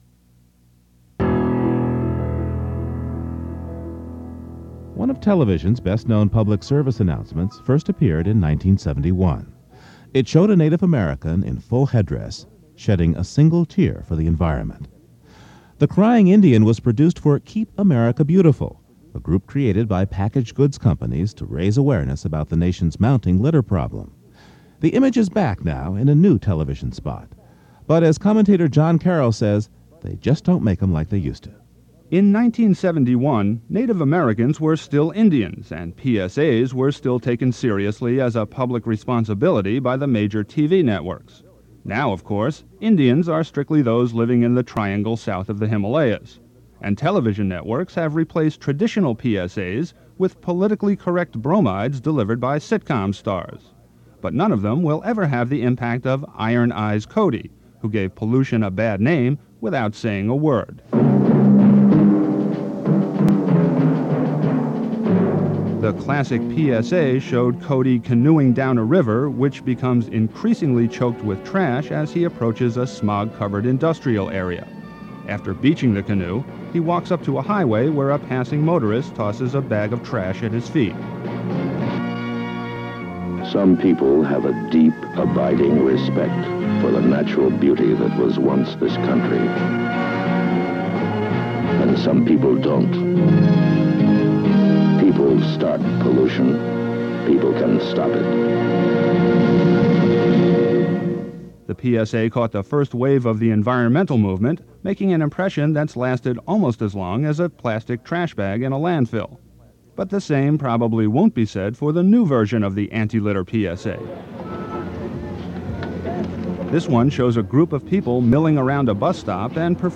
So in May of 2007 I produced a commentary on the return, in a public service announcement, of Iron Eyes Cody, who had years earlier memorably shed a tear over the pollution of America.